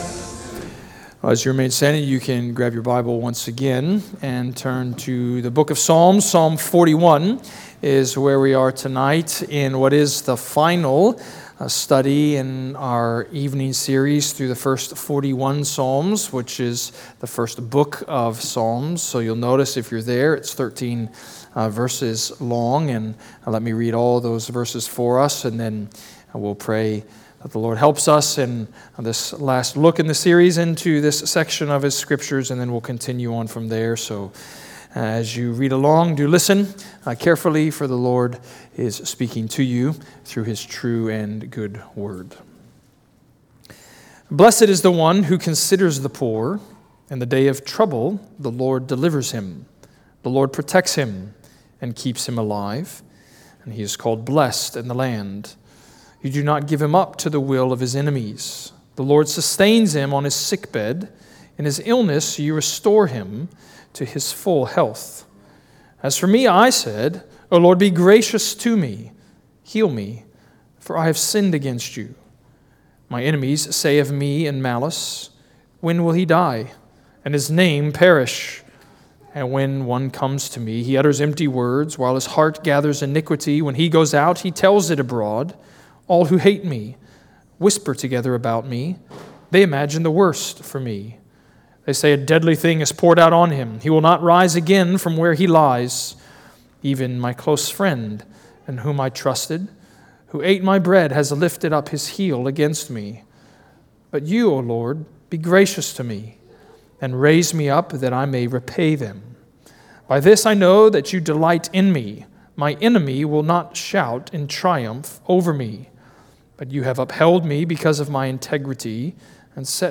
Redeemer Presbyterian Church: Sermon Audio